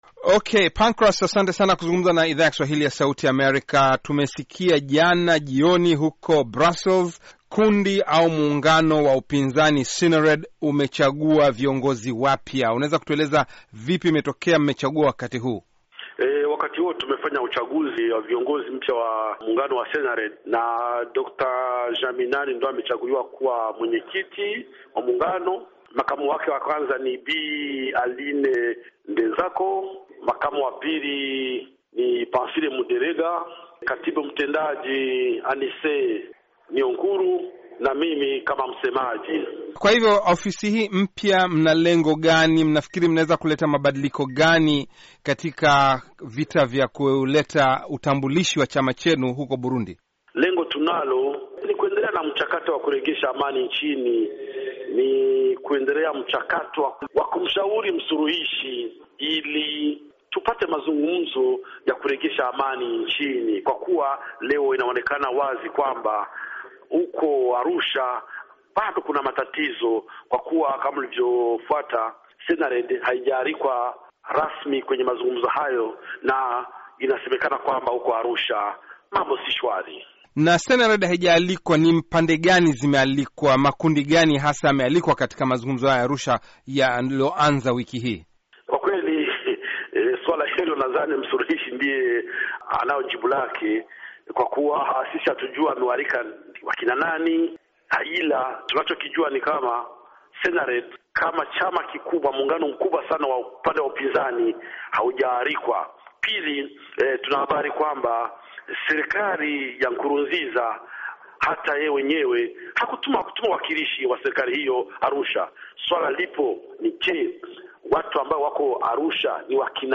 Akizungumza na Sauti ya Amerika kutoka Brussels siku ya Jumanne